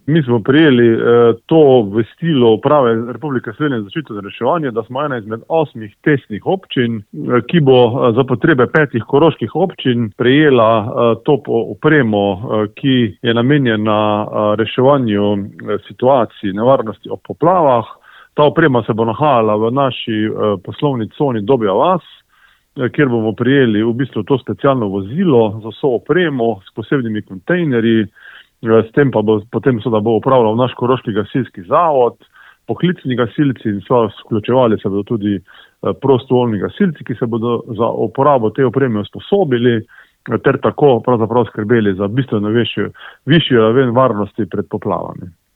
Za kako pomembno pridobitev gre, je pojanil župan Občine Ravne na Koroškem Tomaž Rožen:
IZJAVA ROZEN 1.mp3